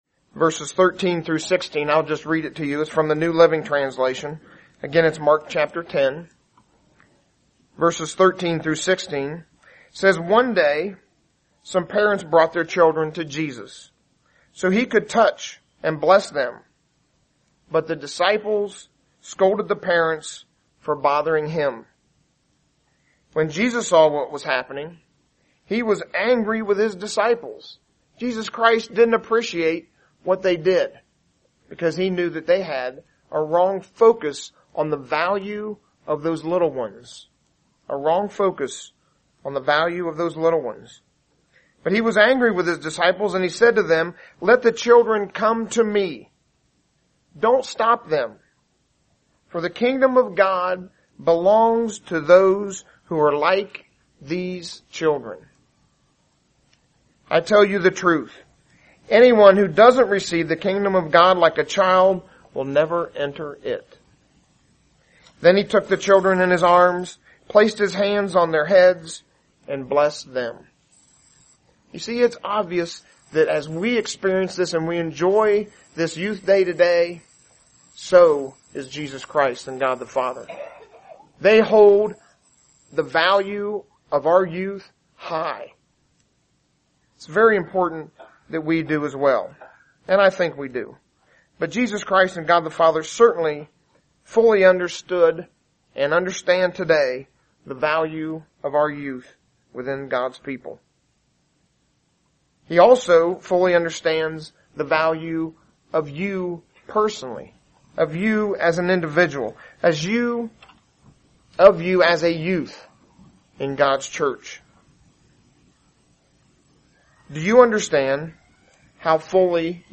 UCG Sermon Notes Notes: Mark 10:13-16 → Jesus was angry at the disciples for rebuking the children and said that no one can enter the Kingdom unless they are like little children.